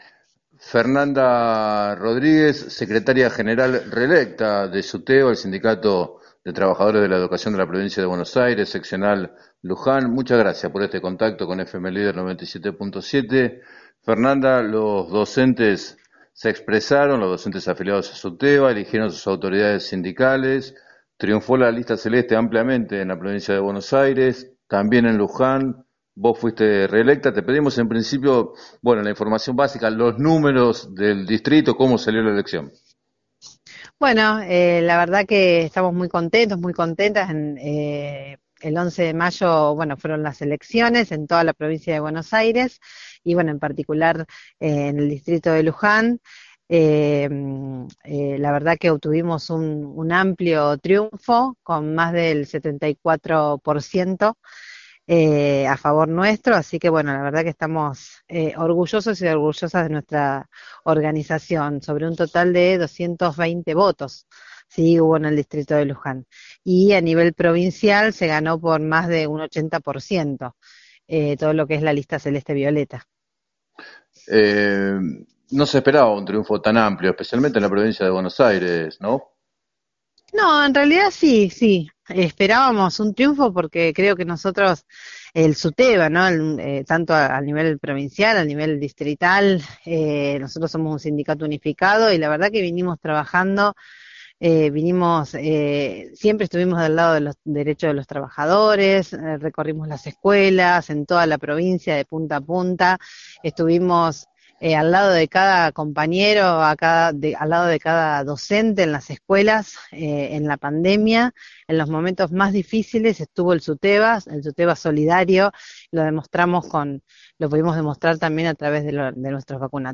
En declaraciones al programa “7 a 9” de FM Líder 97.7